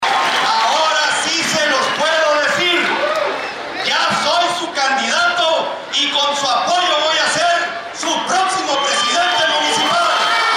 En el marco de la Convención Municipal en Hermosillo, Ernesto de Lucas Hopkins recibió la constancia que lo acredita como el candidato del PRI a la alcaldía de Hermosillo.